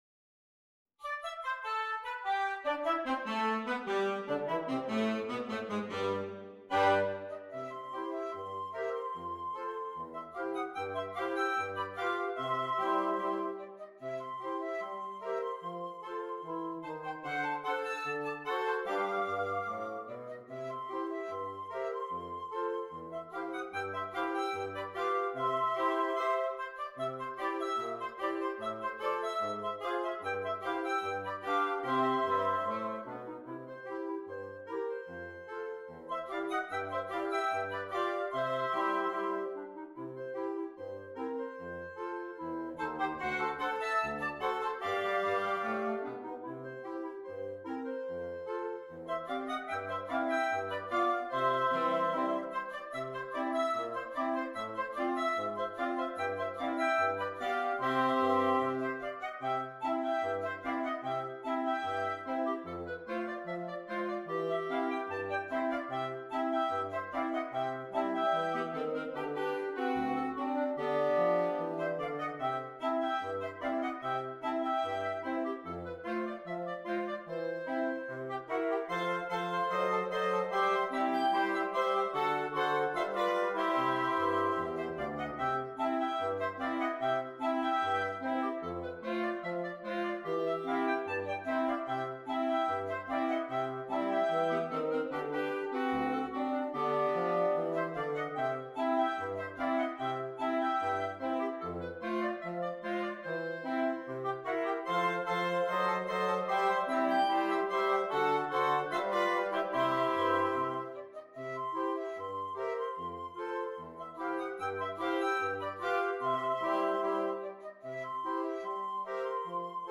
Interchangeable Woodwind Ensemble
PART 1 - Flute, Oboe, Clarinet
PART 6 - Bass Clarinet, Bassoon, Baritone Saxophone